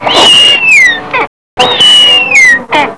snowy_owl.wav